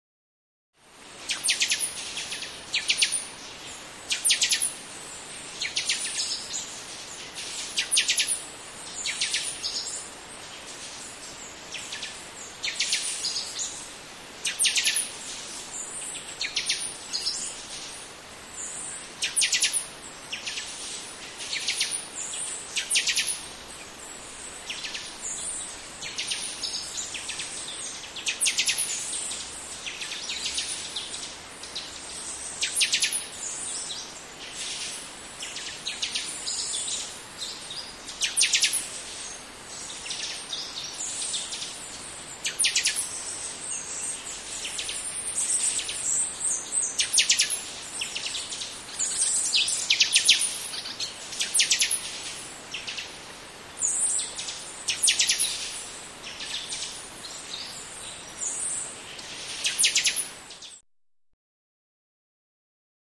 Rain Forest - Dense With Frogs, Crickets, Birds